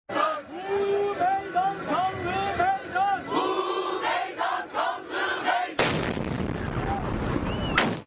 Escucha el momento de una de las explosiones